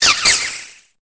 Cri de Bombydou dans Pokémon Épée et Bouclier.